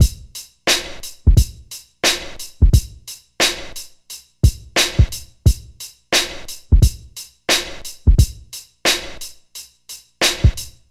88 Bpm Drum Loop Sample F Key.wav
Free drum loop - kick tuned to the F note. Loudest frequency: 2099Hz
88-bpm-drum-loop-sample-f-key-pVN.ogg